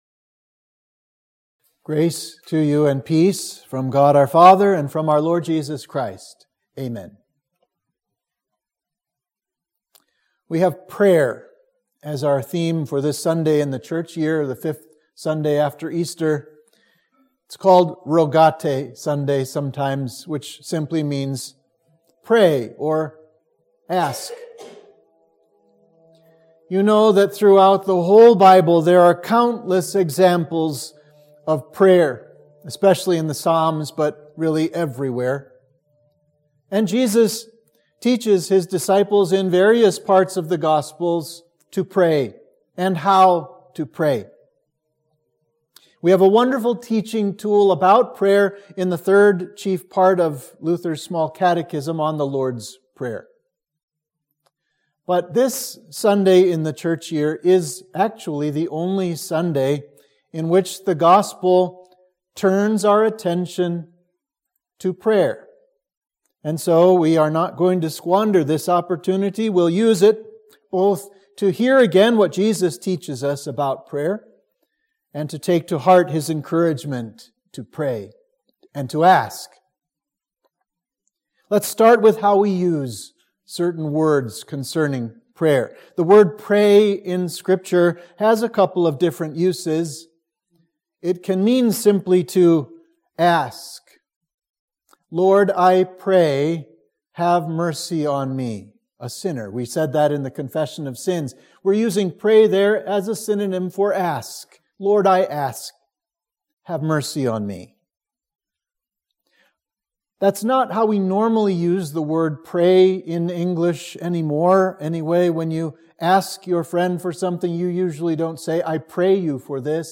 Sermon for Easter 5 – Rogate